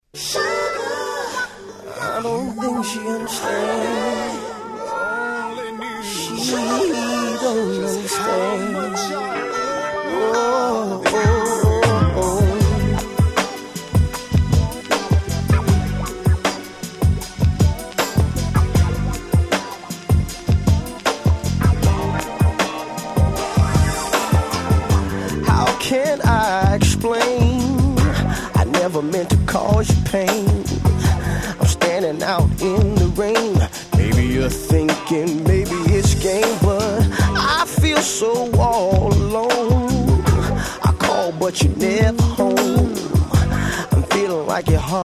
96' Big Hit R&B♪